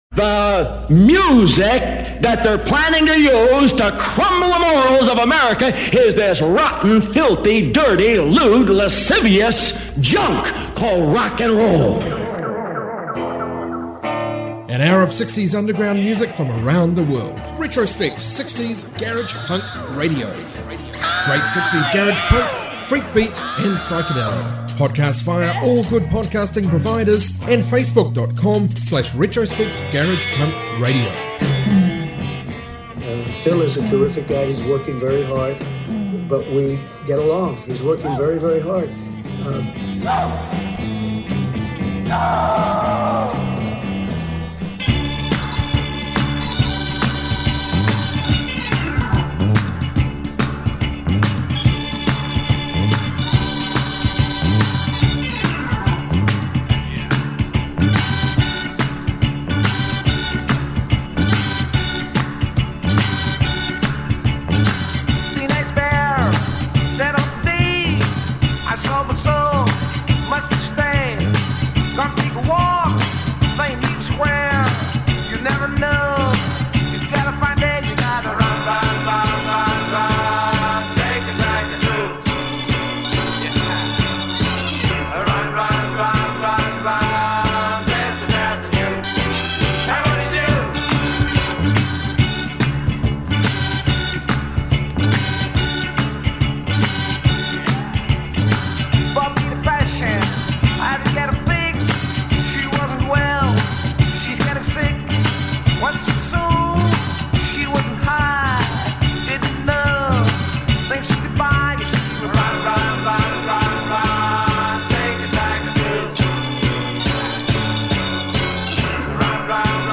60s garage rock podcast